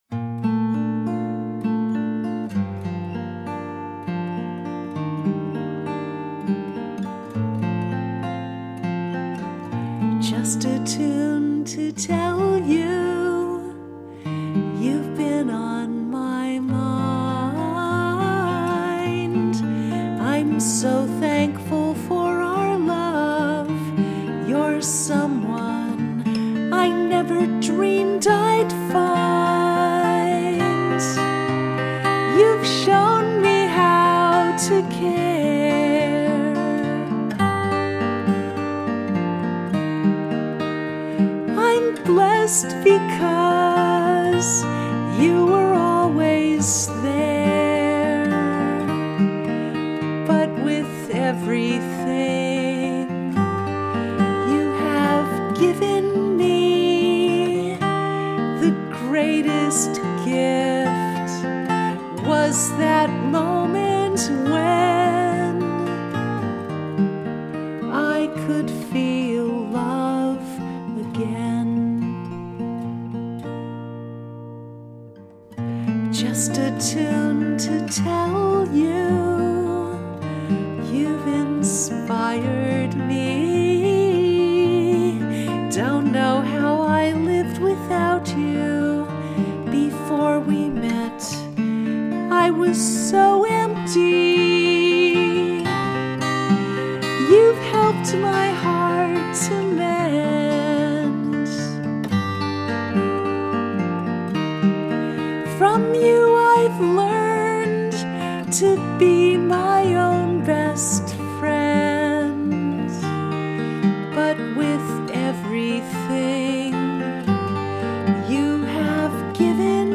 Just a Tune – Solo Piano Melody